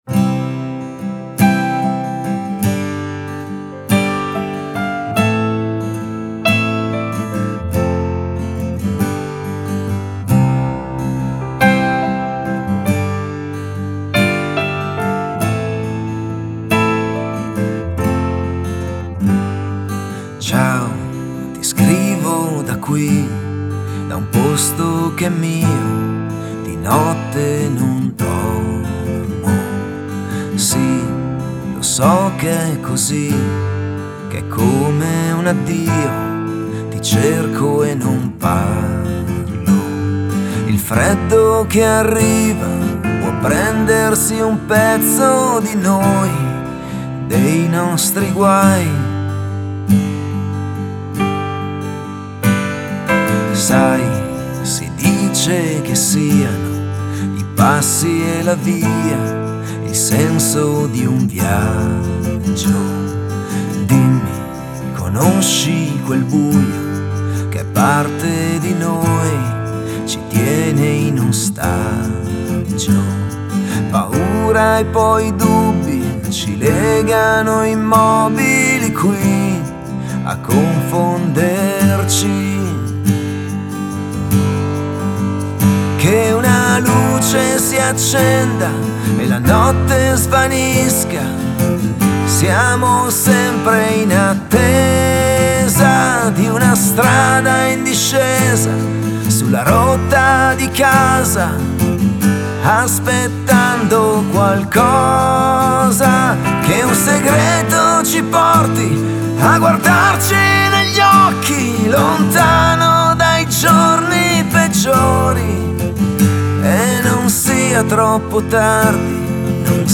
Genere: Cantautori.